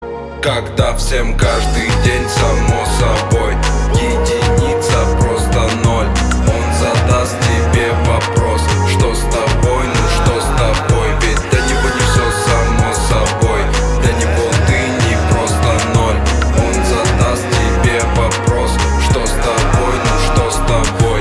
• Качество: 320, Stereo
мужской голос
ритмичные
русский рэп
спокойные